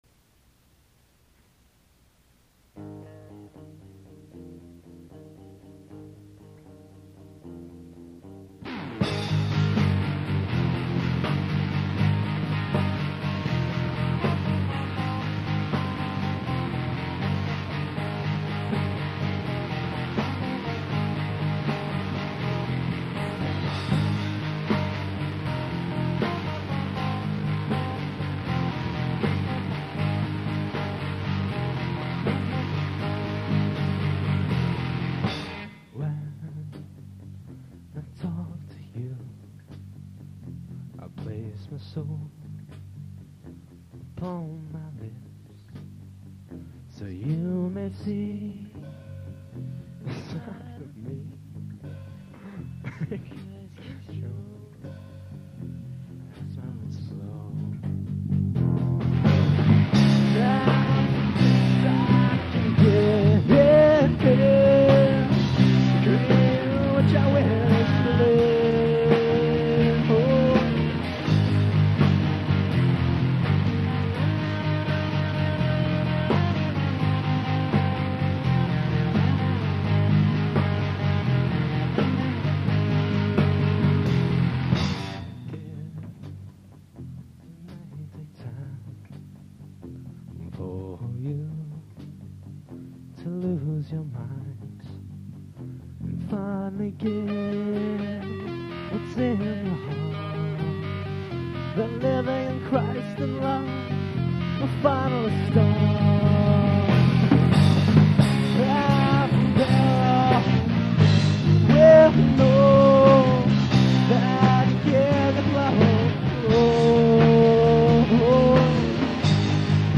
we should have some more mp3's very soon...weve all been kind of busy lately so we havent had much time to do much recording...but we have some live stuff that should be up very very soon. these are live, all three. you can definately tell with "hey there"...because i couldnt stop laughing through my backing vocals ;)